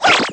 girl_toss_chair.wav